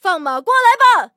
LT-35开火语音1.OGG